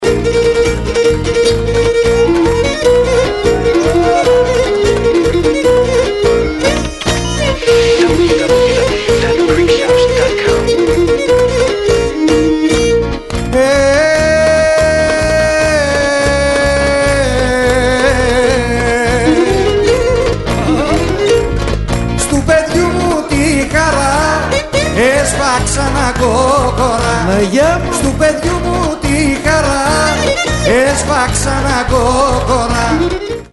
A live album
featuring 22 great traditional-style Greek songs!